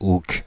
Ääntäminen
Ääntäminen France (Île-de-France): IPA: /uk/ Haettu sana löytyi näillä lähdekielillä: ranska Käännöksiä ei löytynyt valitulle kohdekielelle.